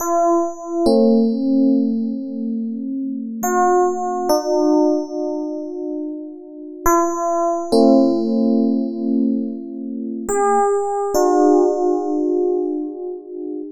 Exodus - Soft Keys.wav